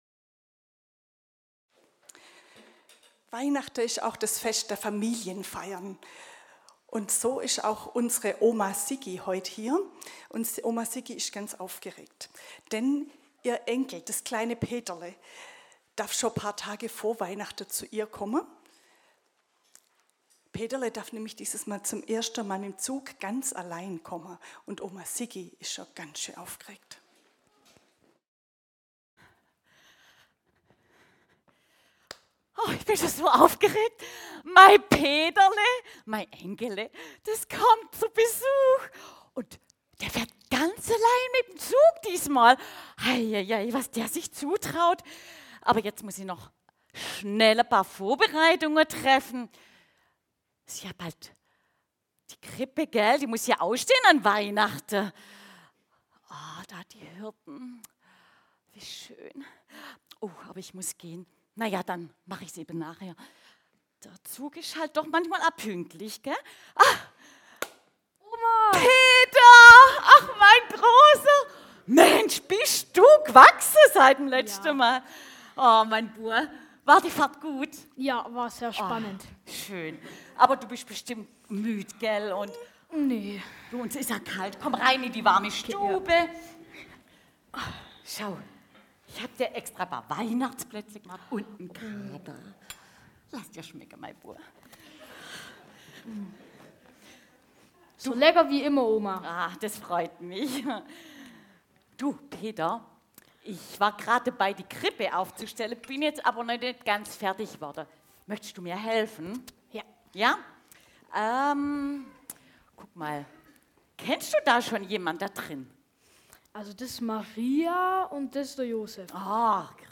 Heilig Abend Gottesdienst ~ Predigten - Gottesdienst mal anders Podcast